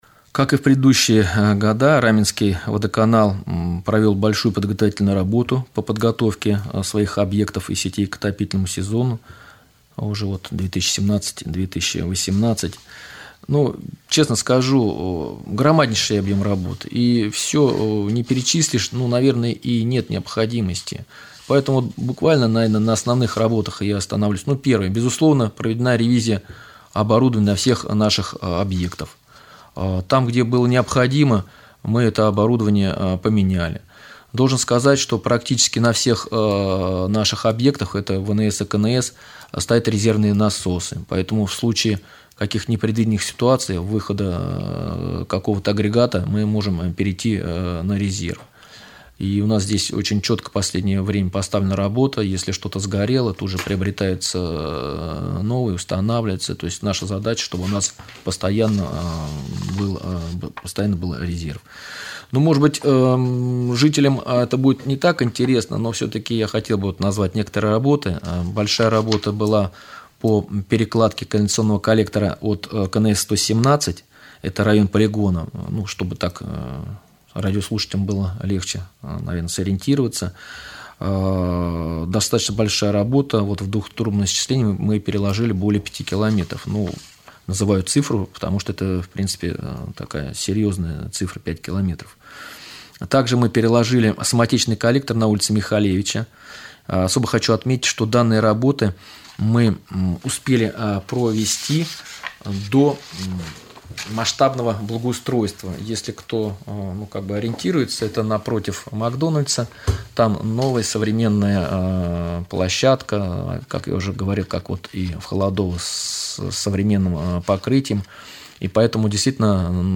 в ходе прямого эфира на Раменском радио